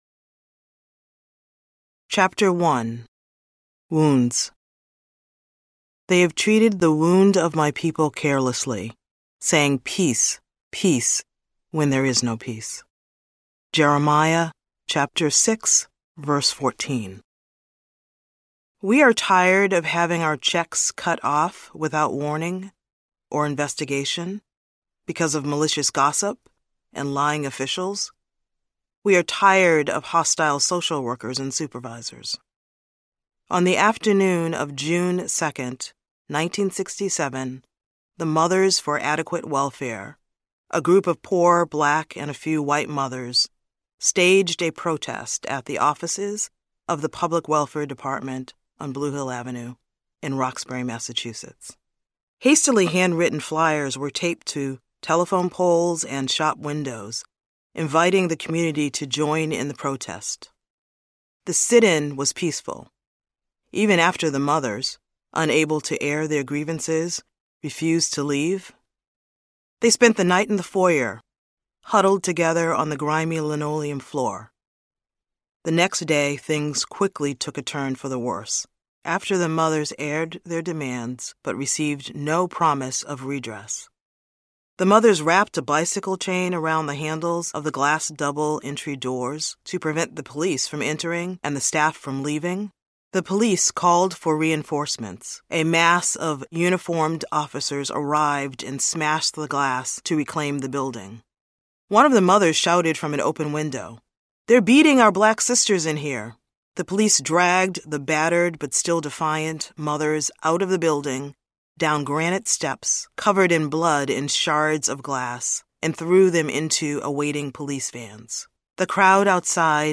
Healing Racial Trauma Audiobook